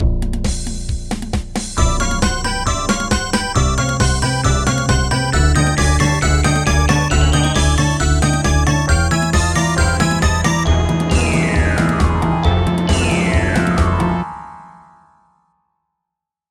Pulled from game files by uploader
Converted from .mid to .ogg
Fair use music sample